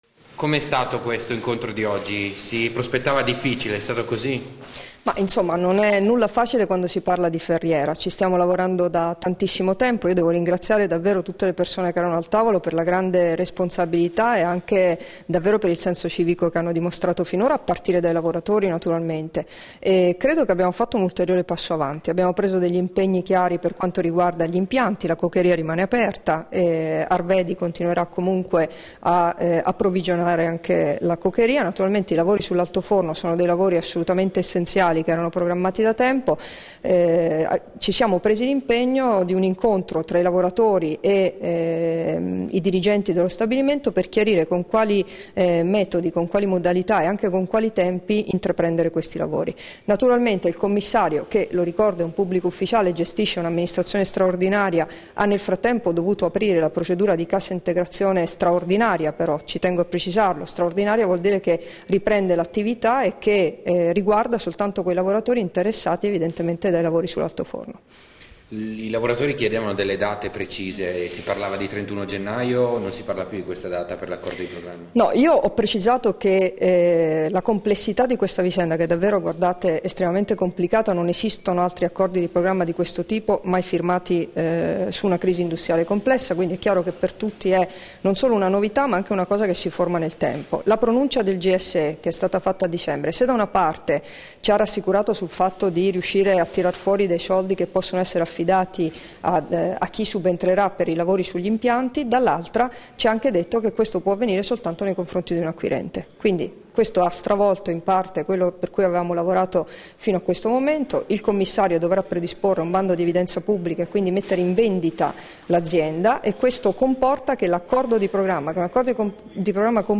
Dichiarazioni di Debora Serracchiani (Formato MP3) rilasciate a margine della riunione del Tavolo sulla Ferriera di Servola a Trieste il 24 gennaio 2014 [2539KB]